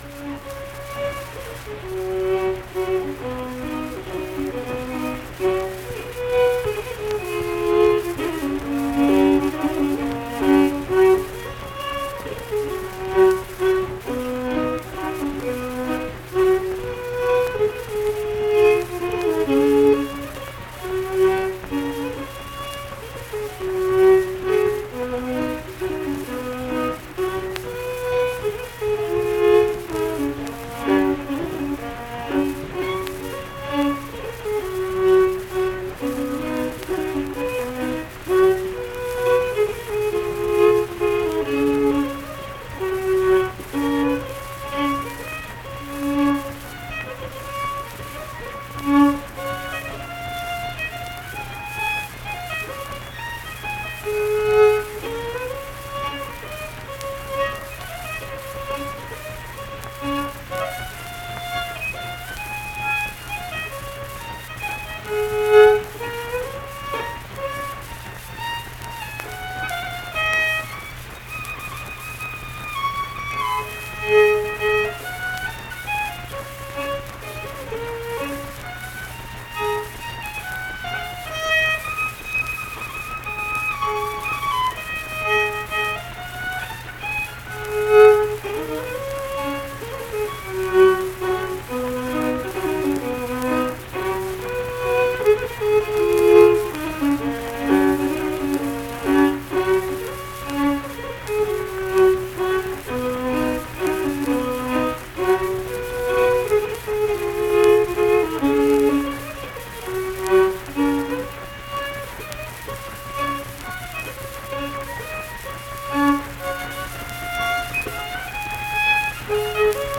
Unaccompanied fiddle music
Verse-refrain 2(3). Performed in Ziesing, Harrison County, WV.
Instrumental Music
Fiddle